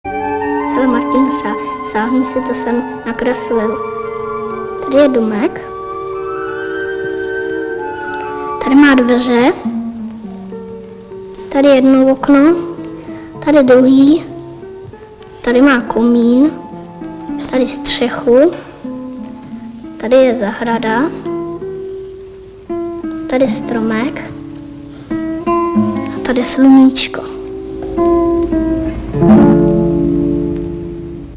• Dokumentární film Heleny Třeštíkové Dotek světla z roku 1979 zachycuje mimo jiné jednoho ze známých propagátorů asistivních technologií, pomůcek a služeb pro zrakově postižené. Poznáte jej z filmové ukázky, i když byl tehdy ještě ve věku, kdy asi nevě děl, co to asistivní technologie jsou?